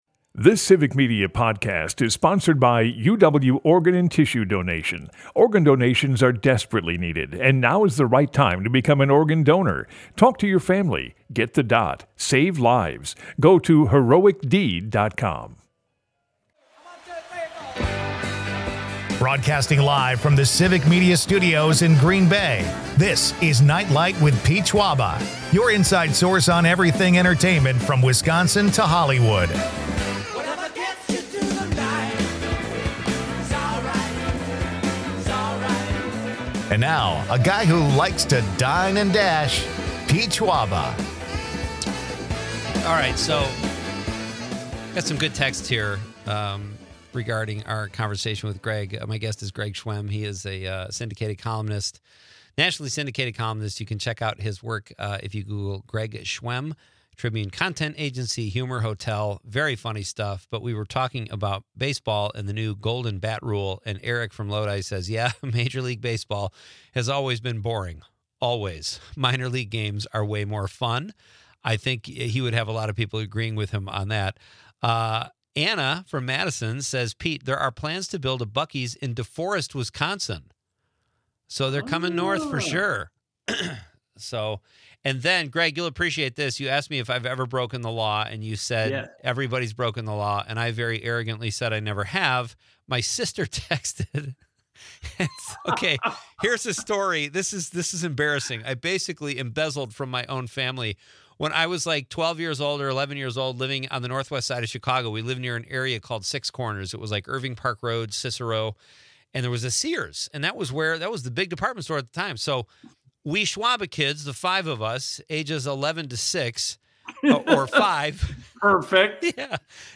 The band talks inspiration, plays a couple tunes, and a new album!